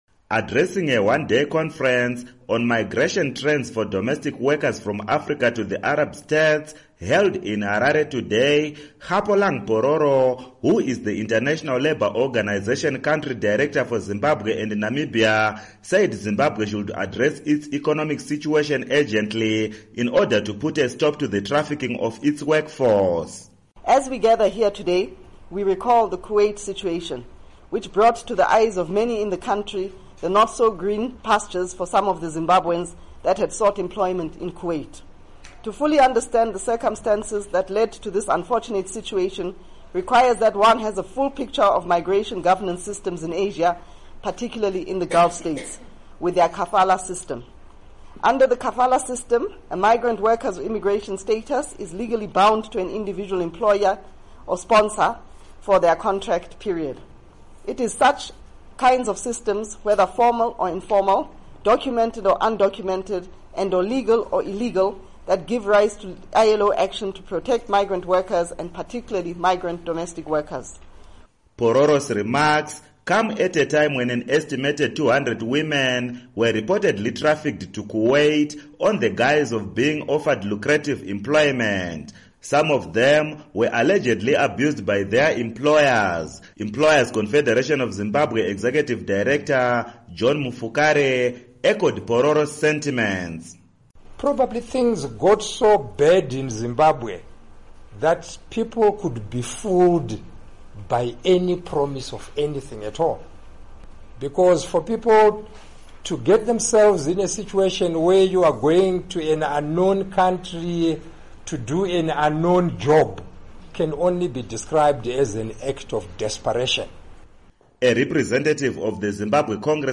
HARARE —